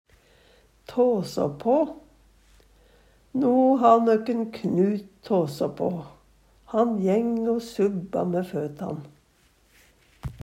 DIALEKTORD PÅ NORMERT NORSK tåså på tase- skrante, veikne Eksempel på bruk No ha nøkk han Knut tåså på.